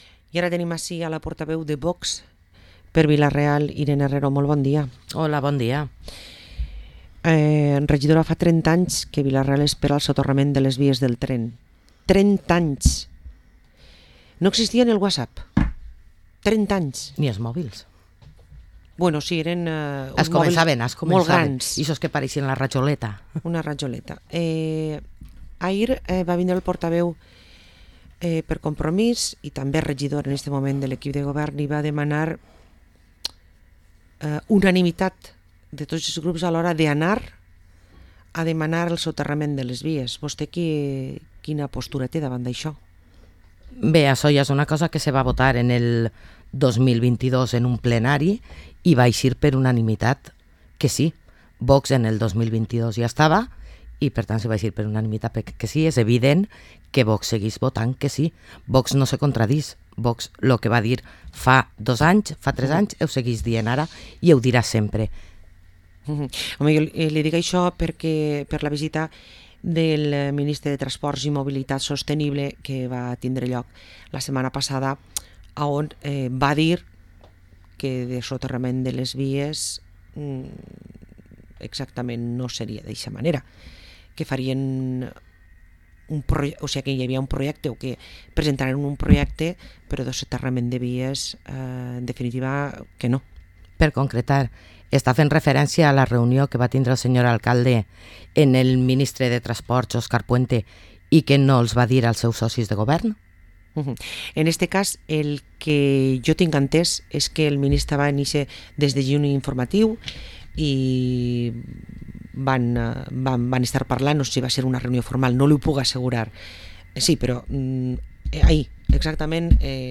Parlem amb Irene Herrero, portaveu de VOX a l´Ajuntament de Vila-real